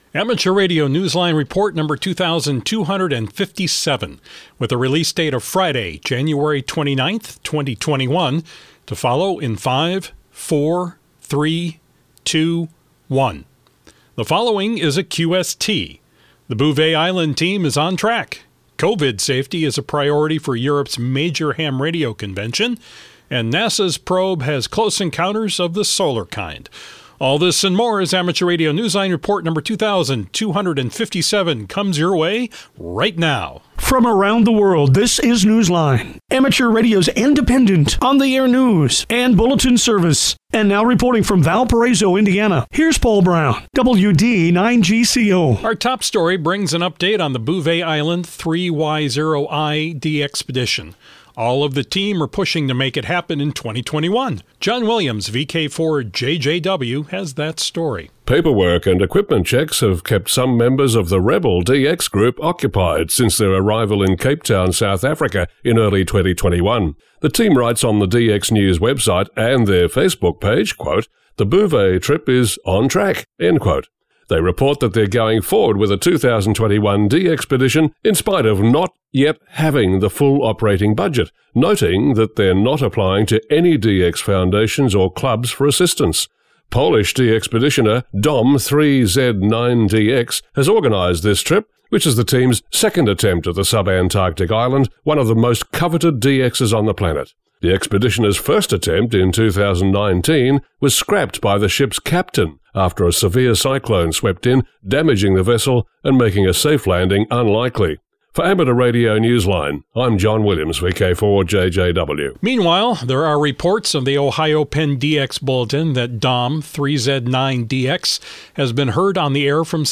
Amateur Radio related weekly news digest